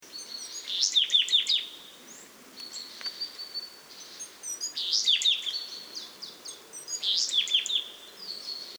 Weidenmeise Parus montanus Willow Tit
Innernzell FRG, 25.01.2014 9 s Gesangsvariante